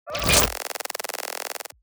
UIGlitch_Granular Analog Glitch 22_JSE_GL.wav